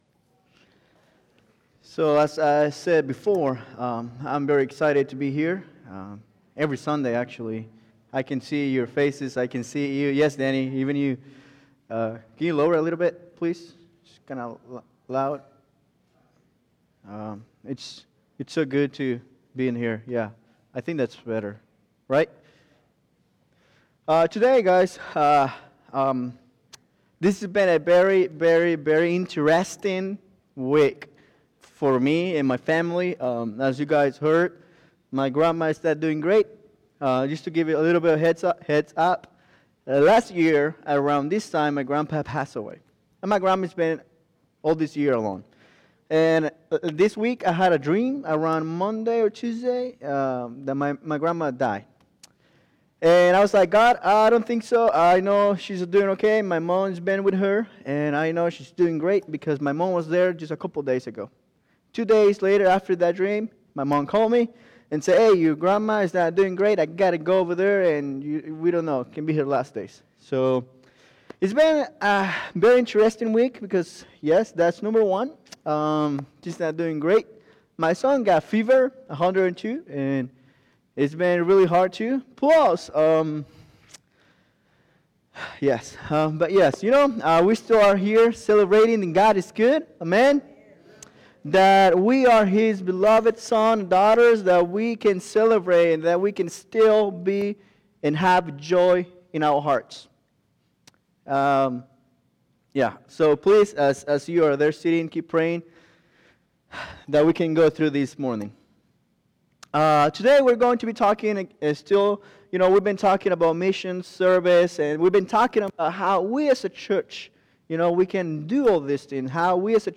Today, as we go into our time to hear and respond to a message from God’s Word.